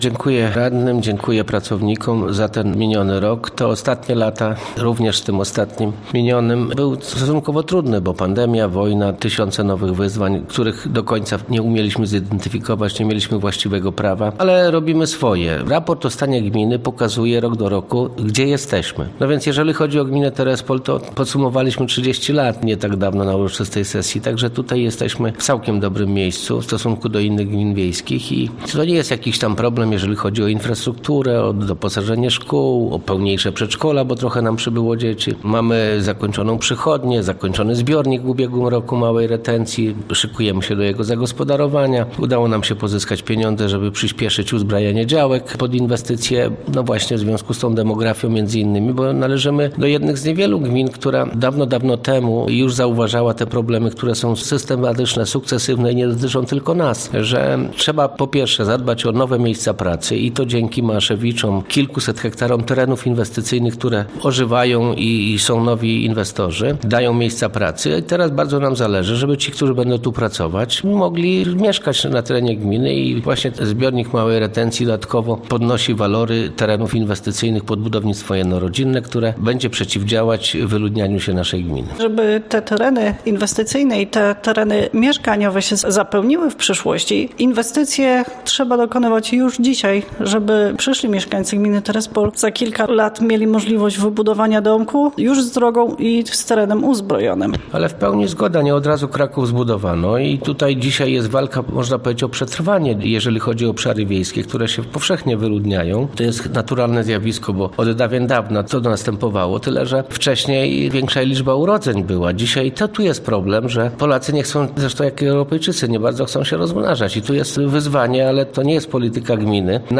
Więcej o sesji oraz o szczegółach raportu o Stanie Gminy Terespol opowiedział w rozmowie z Radiem Podlasie, Wójt Krzysztof Iwaniuk.